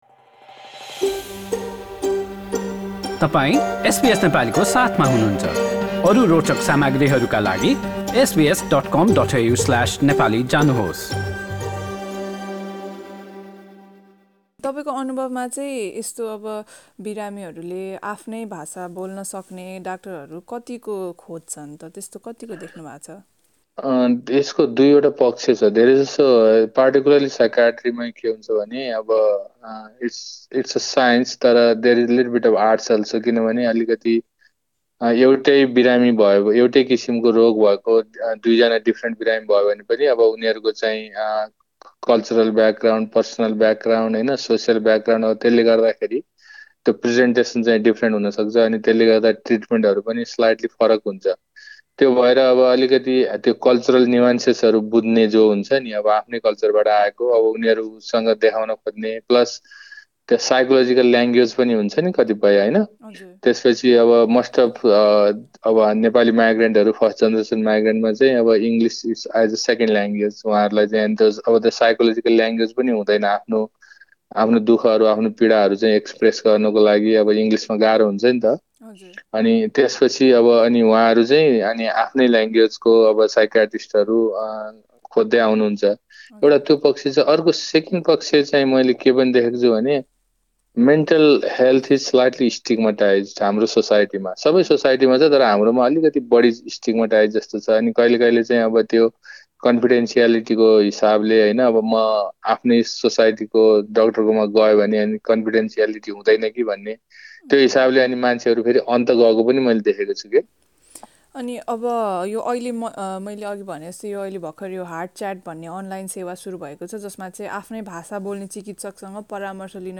This news report is available in the Nepali language version of our website.